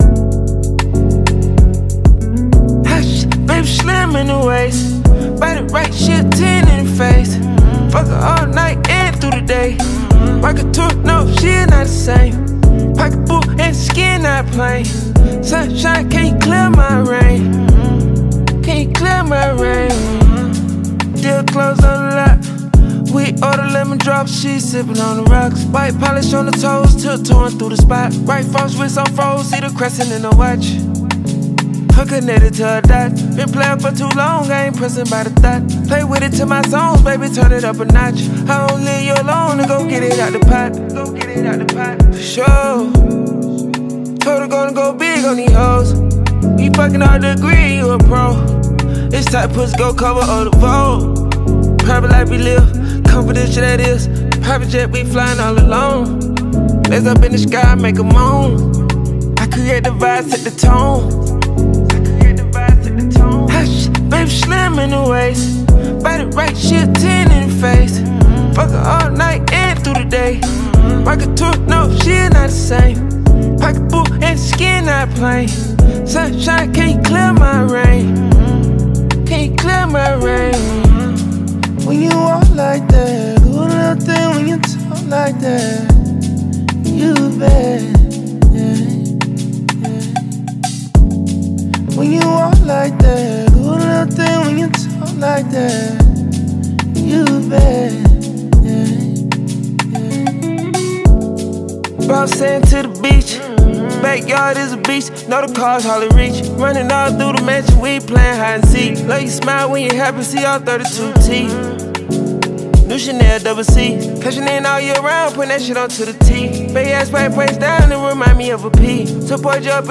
With its high energy tempo and catchy sounds